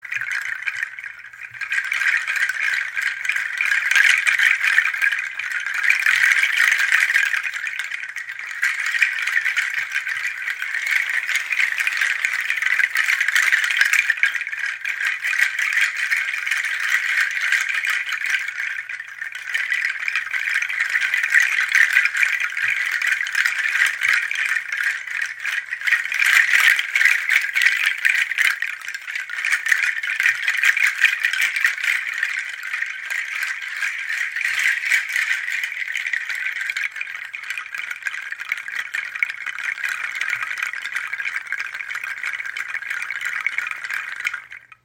Pangi Nussschalen Rassel | Sanfter Wasserklang für Klangräume und Rituale
Beim sanften Bewegen entsteht ein warmer, wasserähnlicher Klang, der an fließende Bewegungen und die beruhigende Kraft der Natur erinnert.
Ein sanfter, fliessender Klang wie Wasser, der Dich umhüllt, den Geist beruhigt, Körper und Seele sanft in Einklang bringt und Räume der Achtsamkeit öffnet, in denen Du loslassen, fühlen und Dich von der harmonischen Schwingung tragen lassen kannst.
Beim behutsamen Spielen entfaltet sich ein warmer, wasserähnlicher Klang – weich, umhüllend und fliessend. Wie ein Bach, der über Steine gleitet, schafft sie eine Atmosphäre von Ruhe und Geborgenheit.
Ihr rhythmischer, gleichmässiger Klang unterstützt dabei, das Nervensystem zu beruhigen, den Atem zu vertiefen und sanft in meditative Zustände zu gleiten.